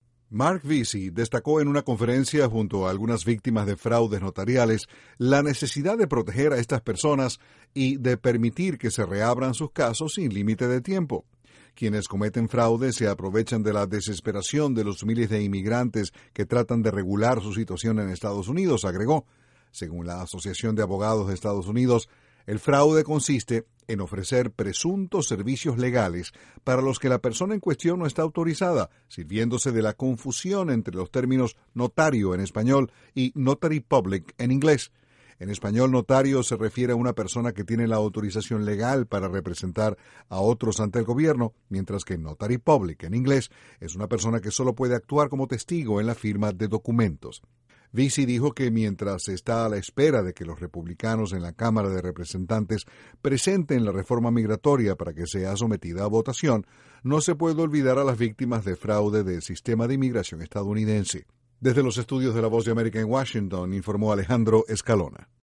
INTRO El representante demócrata de Texas, Marc Veasey, presentó una propuesta de ley para proteger a los inmigrantes de fraudes notariales en el trámite de sus documentos migratorios. Desde la Voz de América en Washington informa